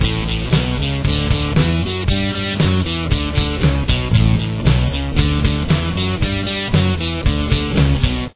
Guitar 1